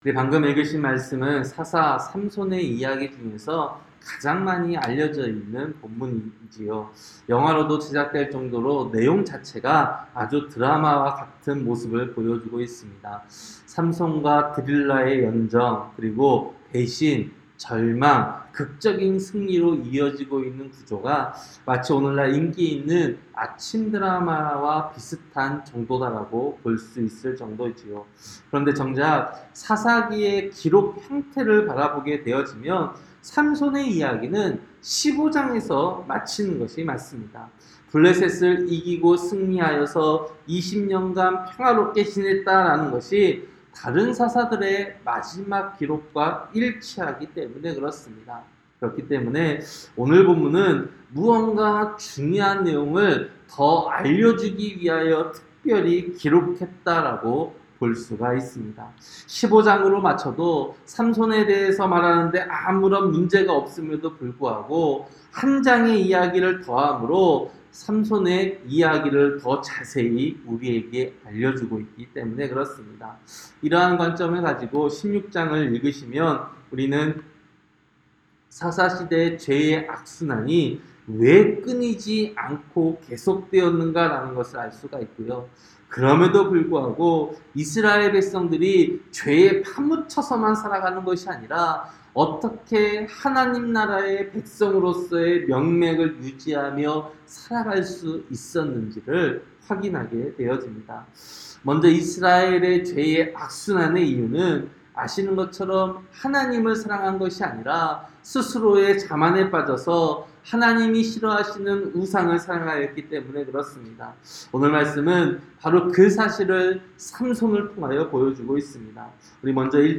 새벽설교-사사기 16장